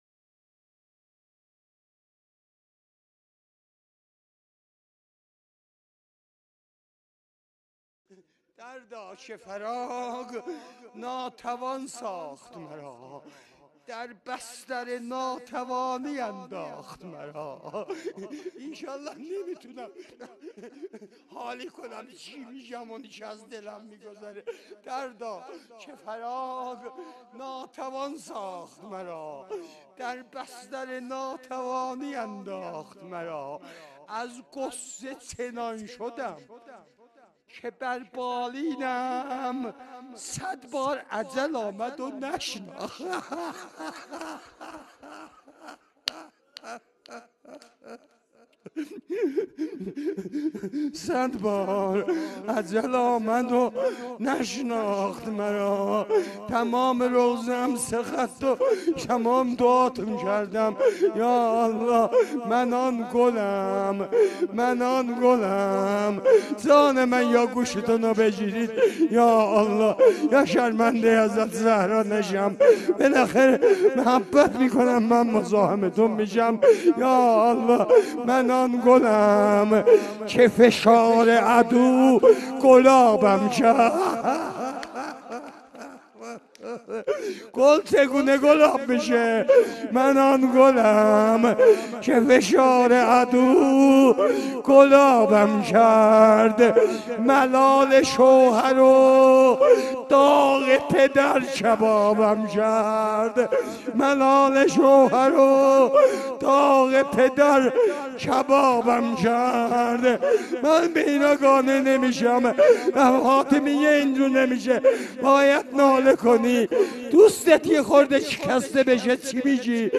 روضه خوانی
روز اول فاطمیه اول - اسفند ماه سال 1393